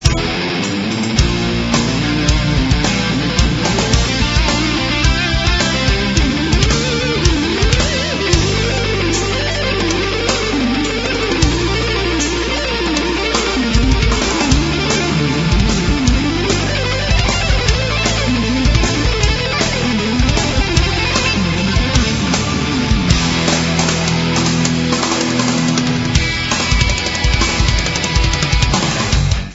Ils sont au format mp3, 32 kbps, 22 KHz, mono.